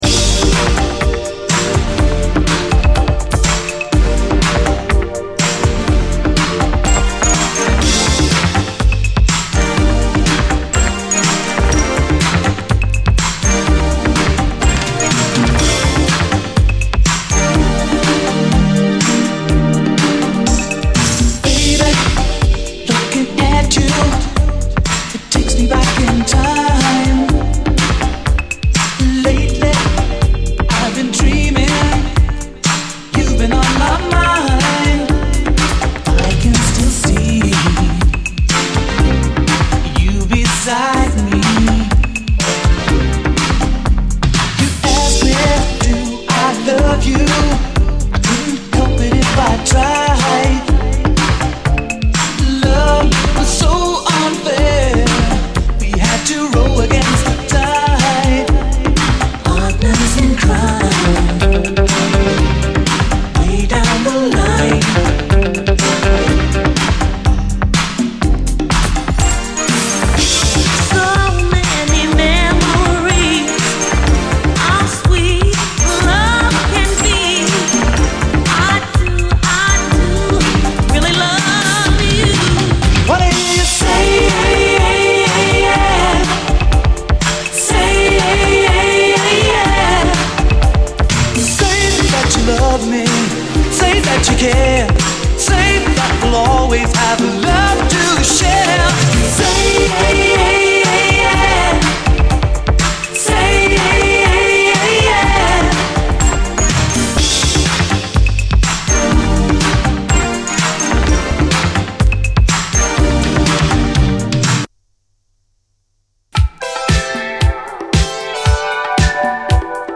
SOUL, 70's～ SOUL, DISCO, SSW / AOR, ROCK
幻想的なシンセ・サウンドが美しい一枚
儚く幻想的に響くシンセ・サウンドが美しい